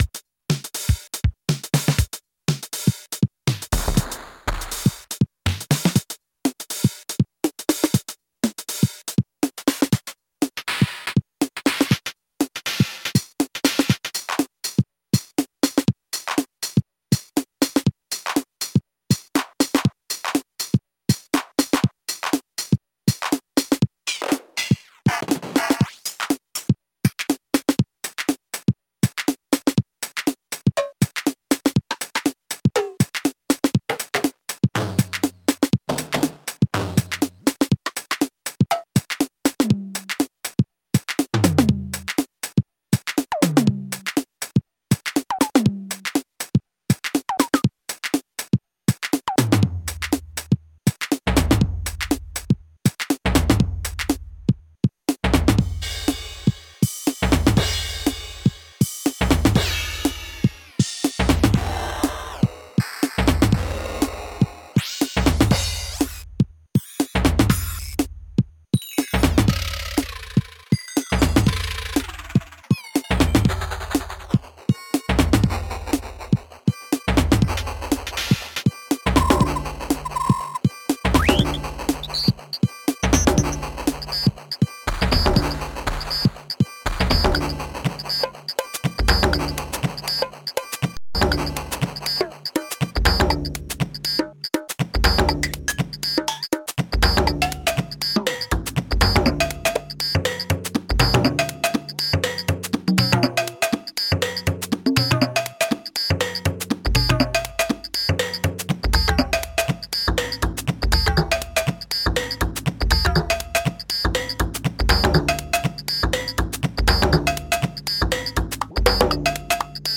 707_pitch_mod.mp3